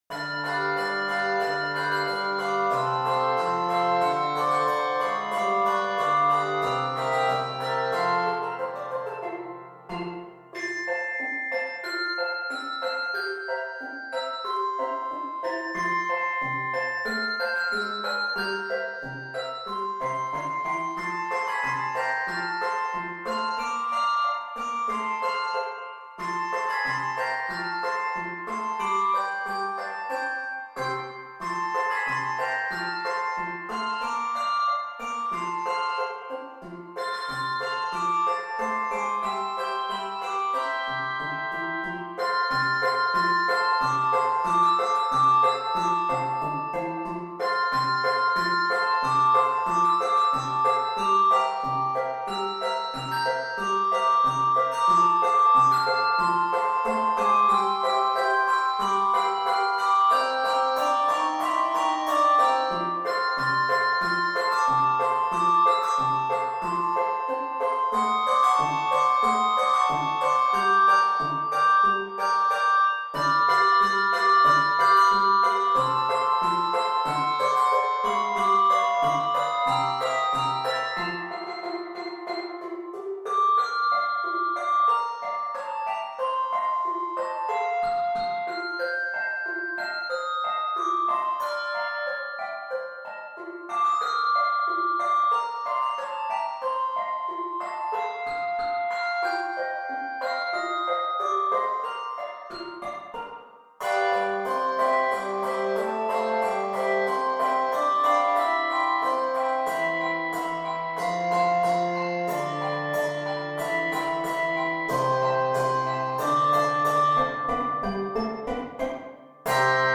Keys of F Major, Bb Major, and C Major. 139 measures.